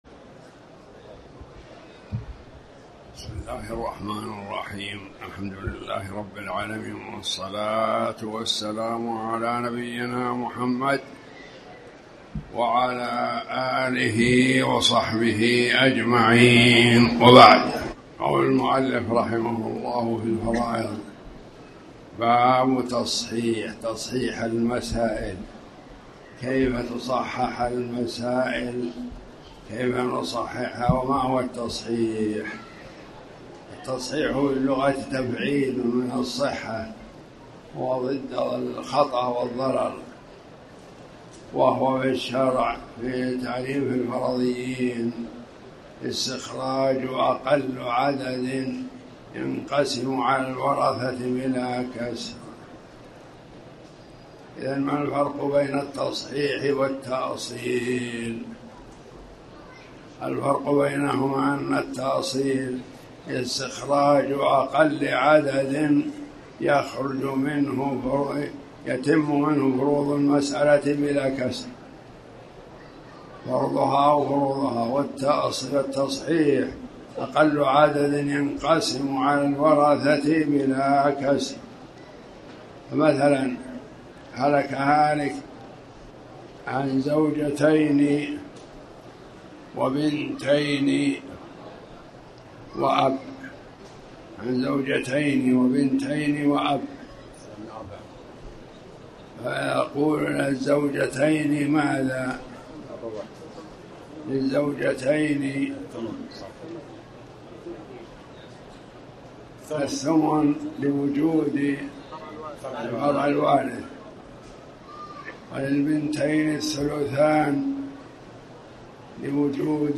تاريخ النشر ١٥ ربيع الثاني ١٤٣٩ هـ المكان: المسجد الحرام الشيخ